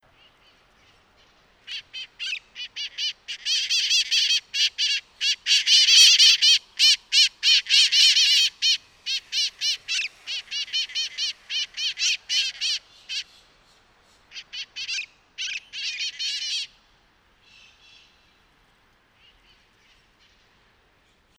VOZ Strident weee weee queiiee queiiee weee weee y loud, bastante brusca cheeah cheeah call que tiene una débil calidad de trompeta de juguete. Más profundo y más duro que Conure con frente rojo.
Aratinga mitrata hockingi - Loro cara roja.wav